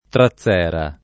[ tra ZZ$ ra ]